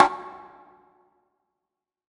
WBONGO SLA1I.wav